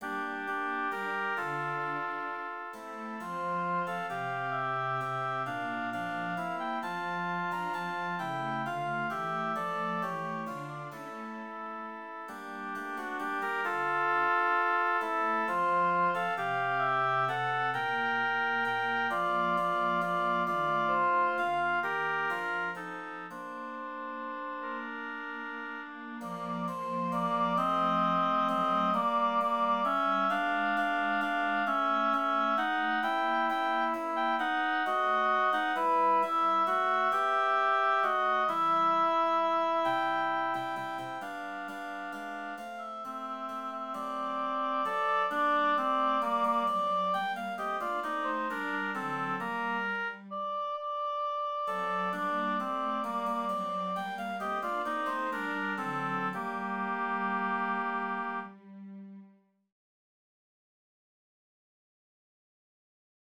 La musica di Corise è scritta ex novo da tal Cheron de Rochesources, pertanto quando Bejamin de La Borde nel suo Essai sur la musique ancienne e moderne riporta l'episodio di Blondel, pubblica la musica con il testo dell'Heritier, ma con una sua nuova musica a quattro parti (evidentemente più arcaica).